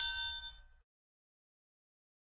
Bell [3].wav